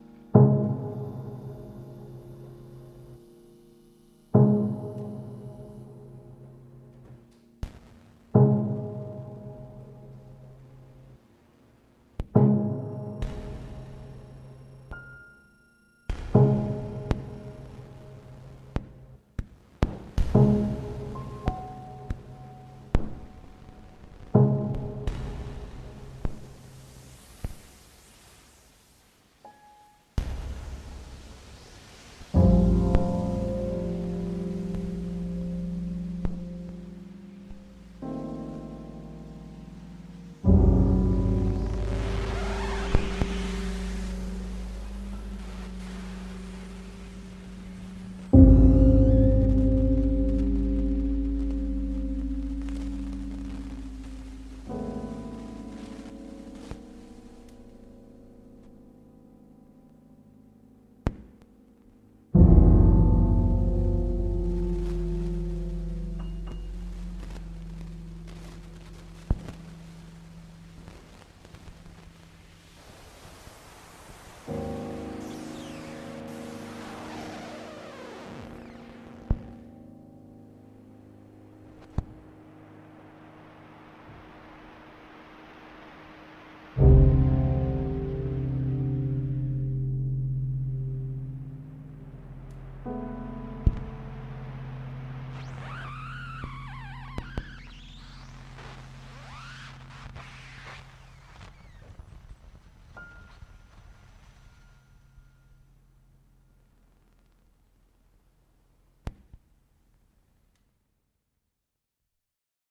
KLAVIER, STEINKOHLE und LIVE-ELEKTRONIK